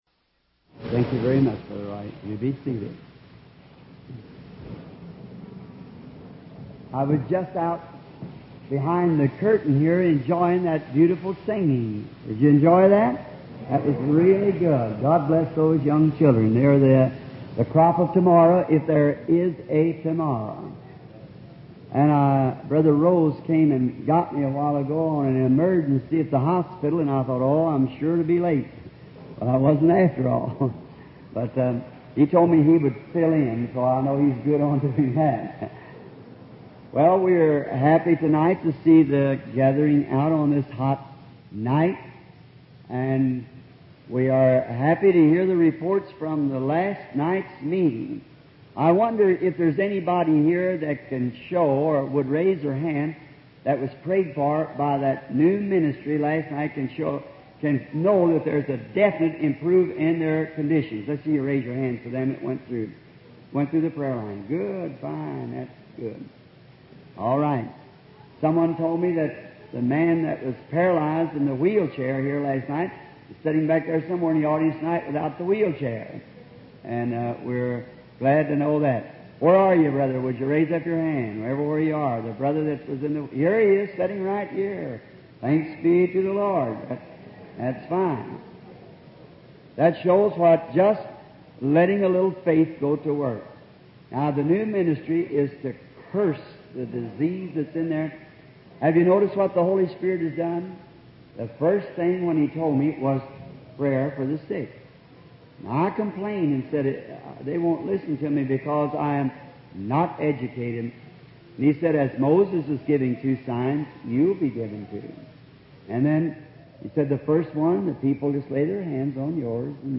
aufgezeichneten Predigten